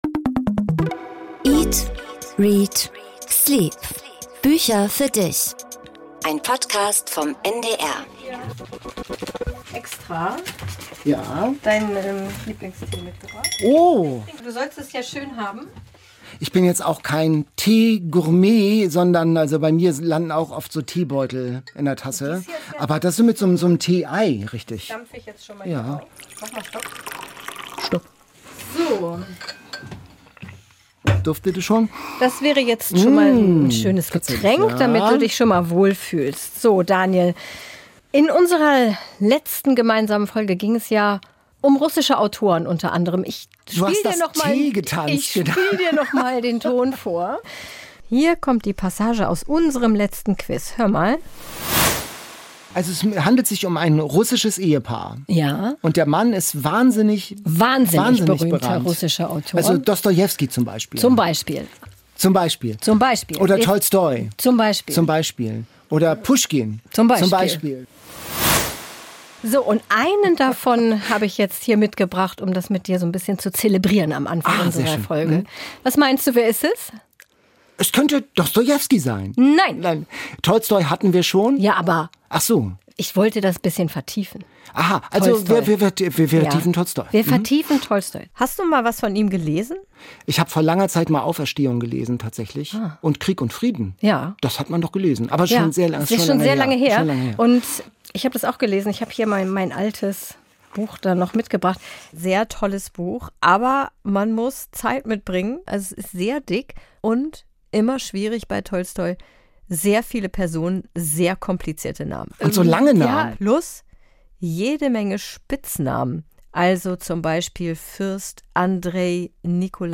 00:29:59 Interview mit Takis Würger